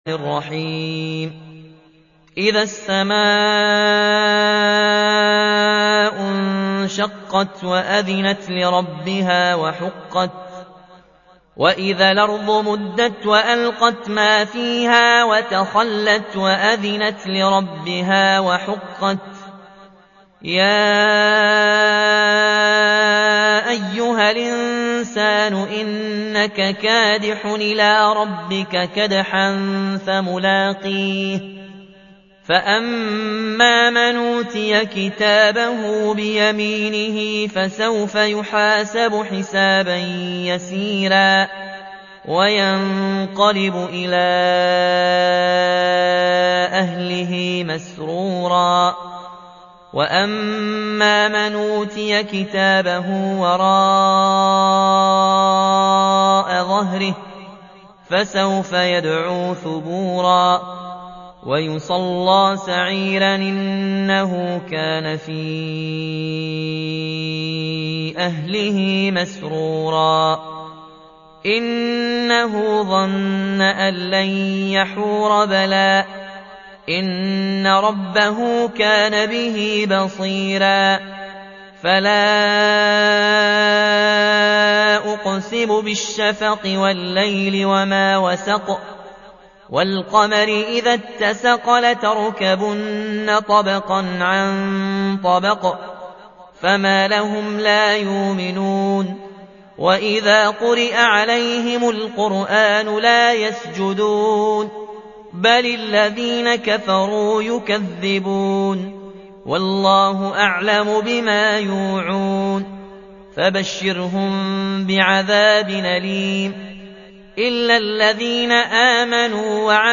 84. سورة الانشقاق / القارئ